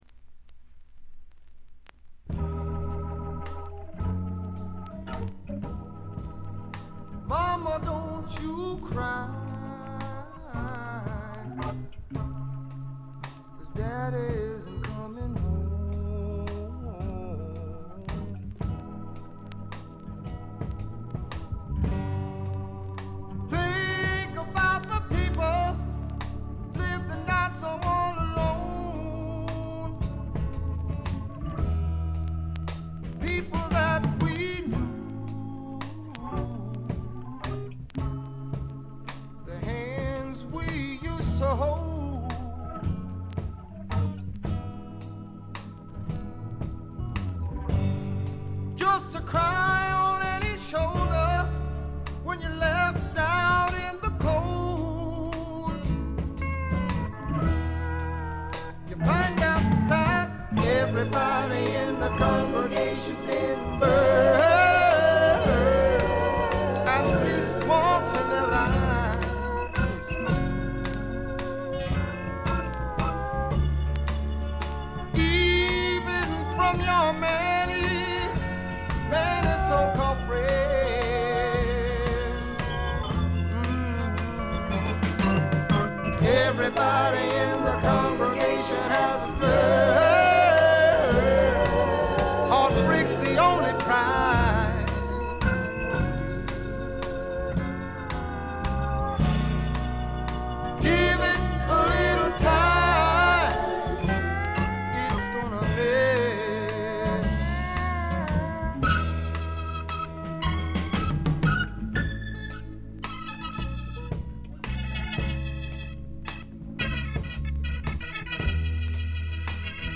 で内容はというとザ・バンドからの影響が色濃いスワンプ裏名盤であります。
試聴曲は現品からの取り込み音源です。